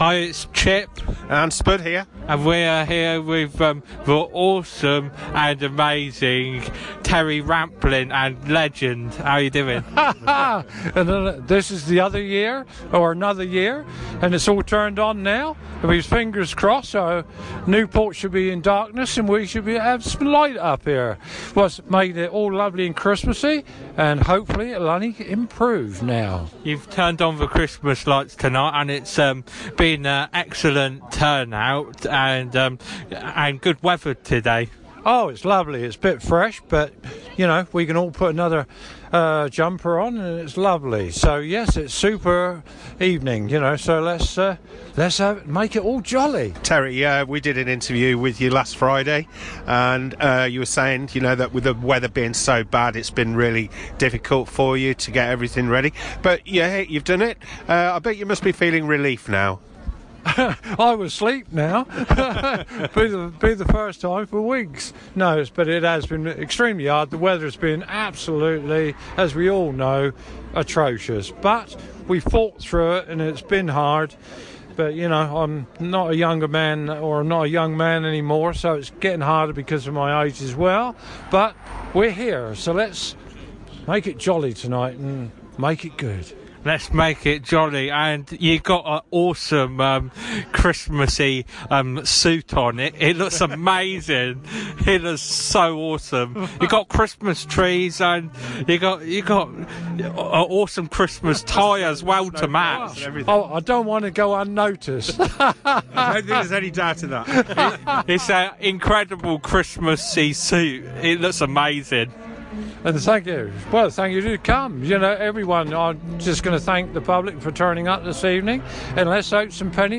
Interview (Long Lain Christmas Light Switch on 2023